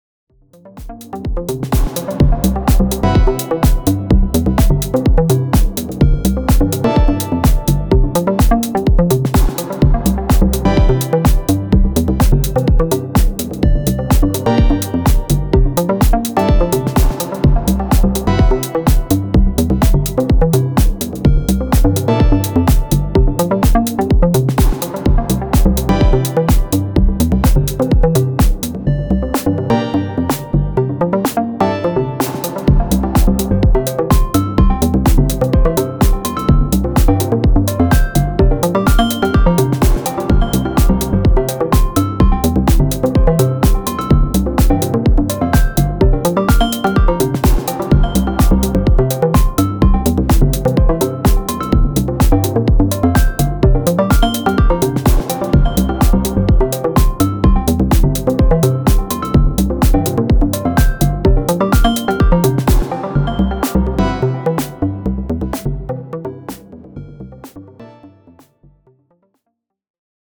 some DN plinks & plonks